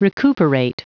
Prononciation du mot recuperate en anglais (fichier audio)
Prononciation du mot : recuperate